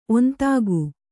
♪ ontāgu